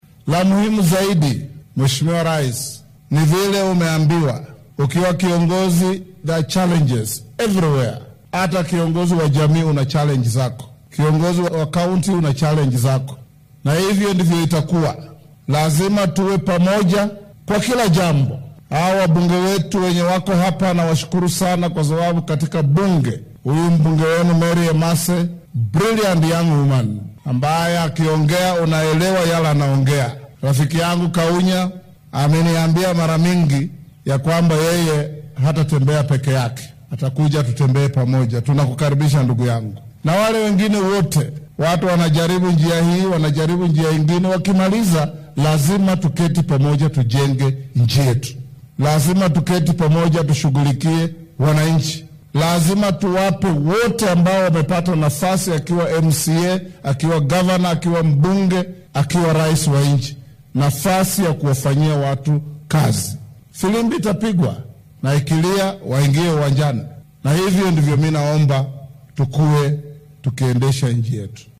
Arrintan ayuu ka sheegay deegaanka Koonfurta Teso ee ismaamulka Busia.